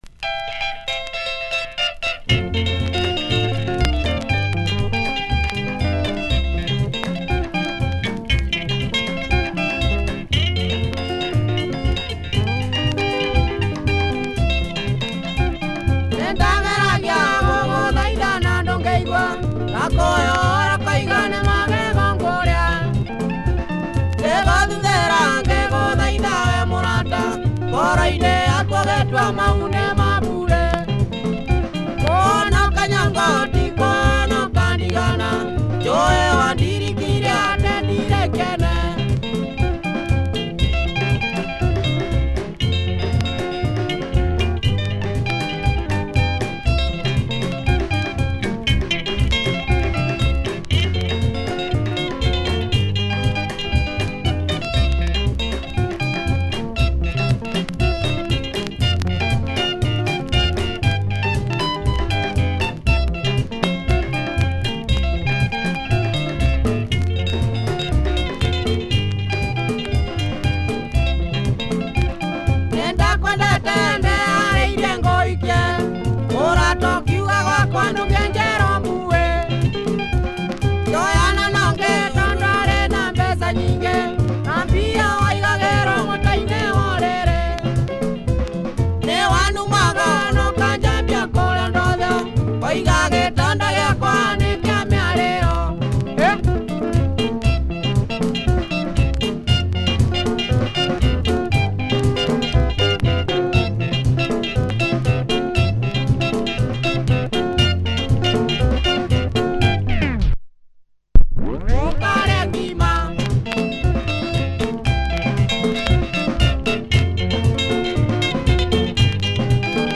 Quality Kikuyu Benga by this famous group https